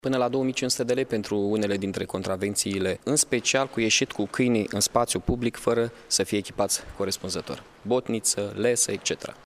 Mihai Chirica a mai spus că amenzile pentru nerespectarea legislaţiei sunt mari: